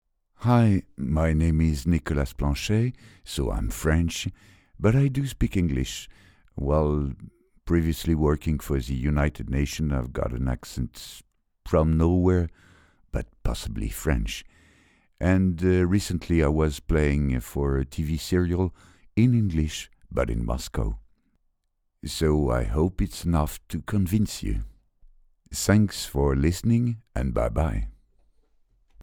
Voix off
Short Demo in English
Jovial, chaleureux ou autoritaire au théâtre ou au micro, "à mon insu de mon plein gré", ma voix grave est déterminante, aussi ai-je appris à en sortir))).